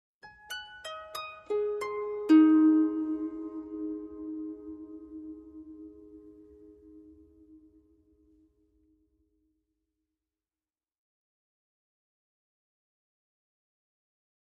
Harp, Arpeggio Reminder, Type 1